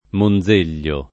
[ mon z% l’l’o ]